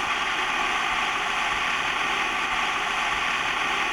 BLOW FX    1.wav